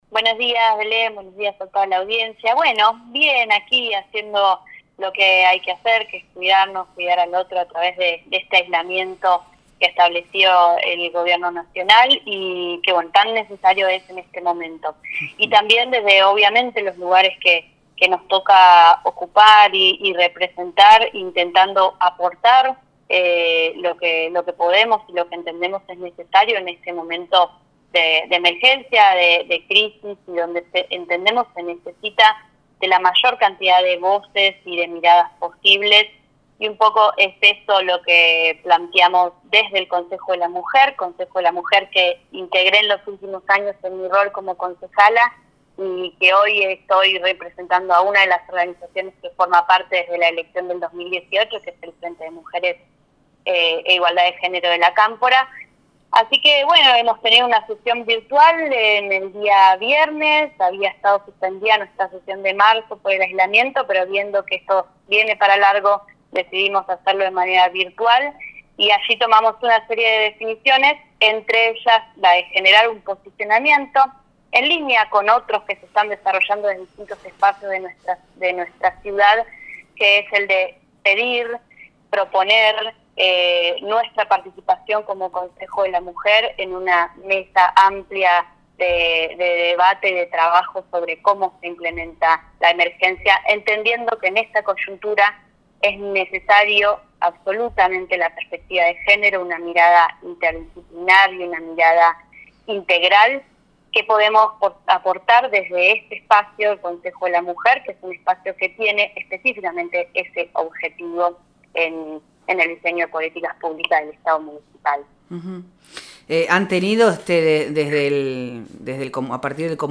Ana Marks, integrante del Consejo de la Mujer en Bariloche por La Cámpora, en diálogo con Proyecto Erre explicó por qué es necesaria la participación de ese espacio que conforman diversas organizaciones, el municipio y el Concejo Deliberante, en una mesa de trabajo ampliada para encarar la crisis sanitaria en la ciudad.